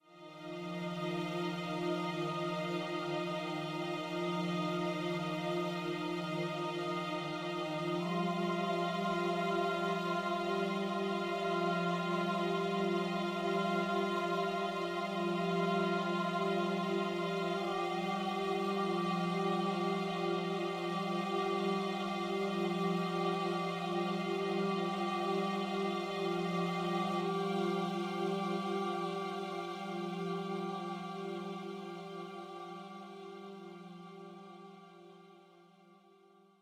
The first piece was an “uneasy” underscoring to the entire scene, I used strings and choir and alternately incremented each piece up a step for 3 minutes, here’s a sample:
smaug-underscoring1.mp3